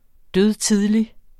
Udtale [ ˈdøðˈtiðli ]